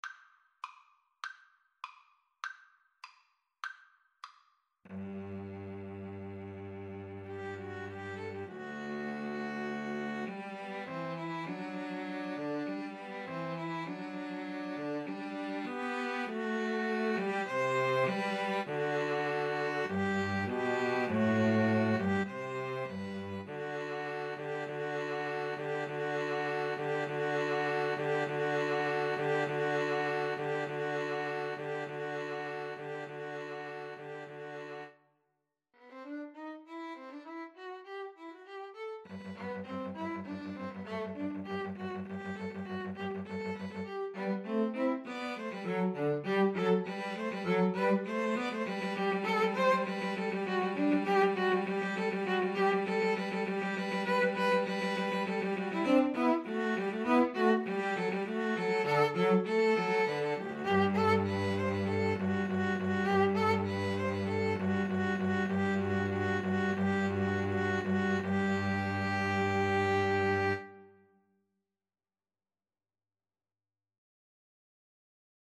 Play (or use space bar on your keyboard) Pause Music Playalong - Player 1 Accompaniment Playalong - Player 3 Accompaniment reset tempo print settings full screen
G major (Sounding Pitch) (View more G major Music for 2-Violins-Cello )
2/4 (View more 2/4 Music)
Classical (View more Classical 2-Violins-Cello Music)